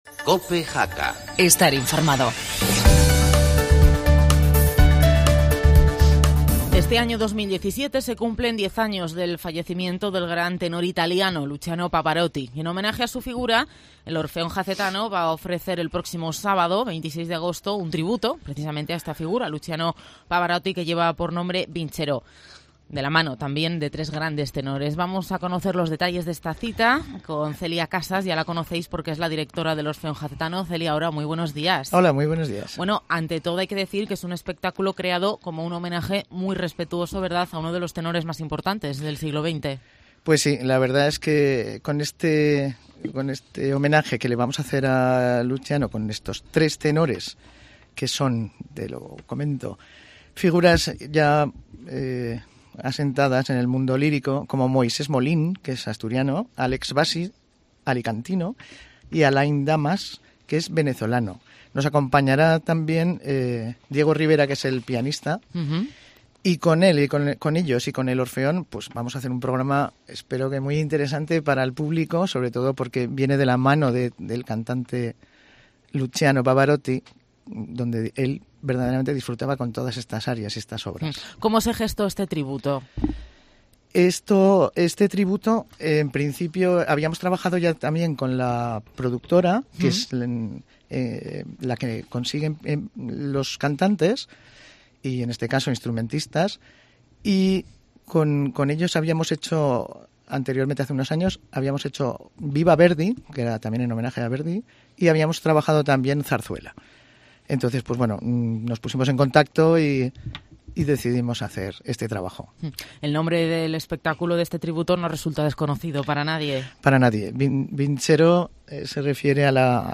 Entrevista Orfeón Jacetano 22 agosto